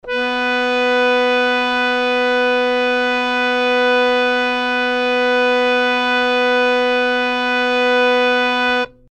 harmonium
B3.mp3